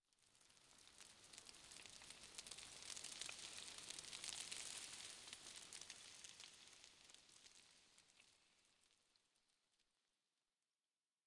Minecraft Version Minecraft Version snapshot Latest Release | Latest Snapshot snapshot / assets / minecraft / sounds / ambient / nether / basalt_deltas / debris1.ogg Compare With Compare With Latest Release | Latest Snapshot
debris1.ogg